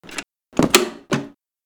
Pistol sound - Audio - ARTEZO
Track Format: Mono